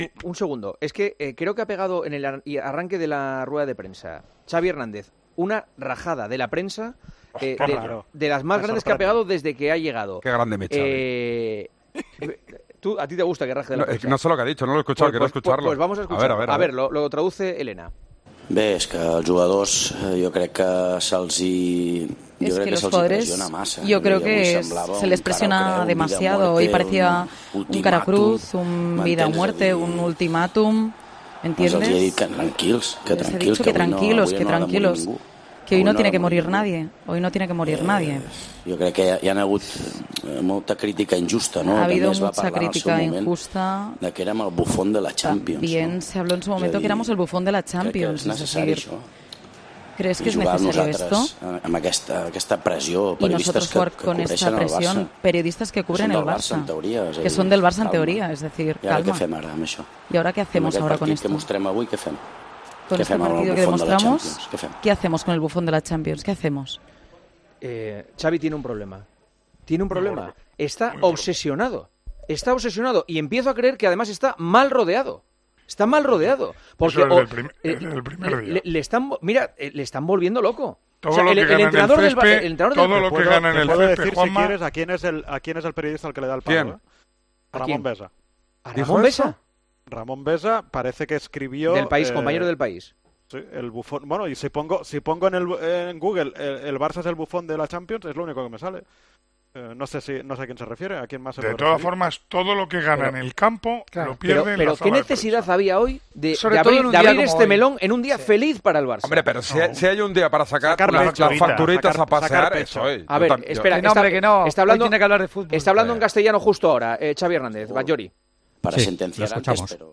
Rueda de prensa del entrenador del FC Barcelona, Xavi Hernández